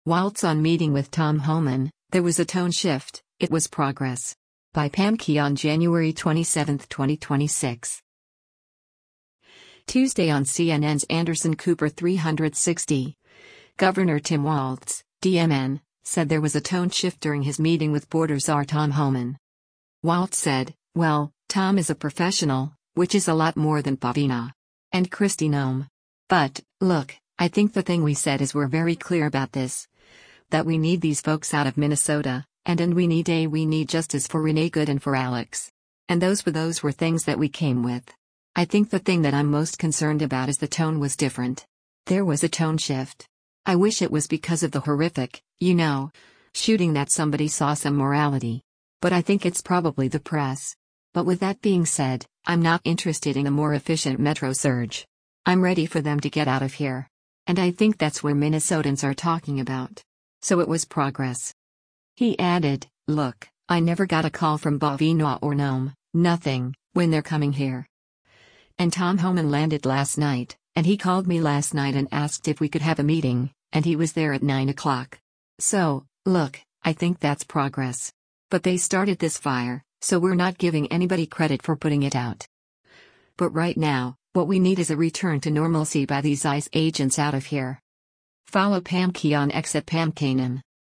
Tuesday on CNN’s “Anderson Cooper 360,” Gov. Tim Walz (D-MN) said “there was a tone shift” during his meeting with border czar Tom Homan.